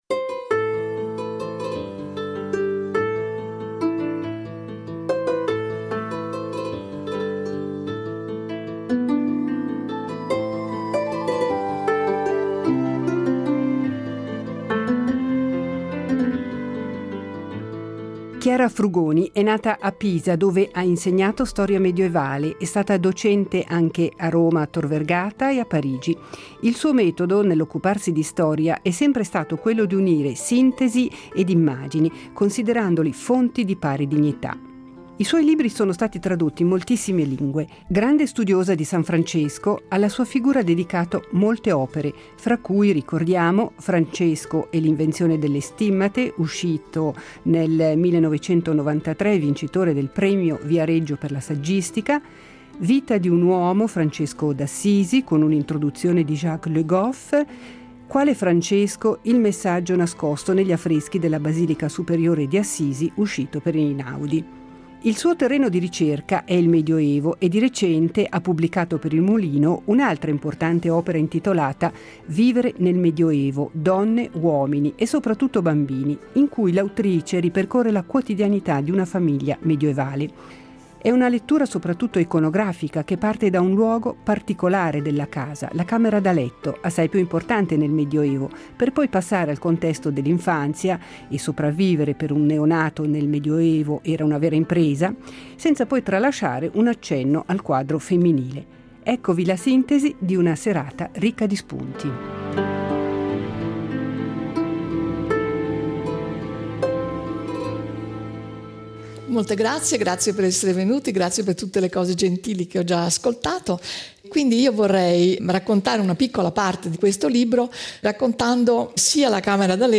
Chiara Frugoni è stata ospite di recente di un incontro pubblico negli studi della RSI. In questo “Laser” vi proponiamo alcuni momenti della serata.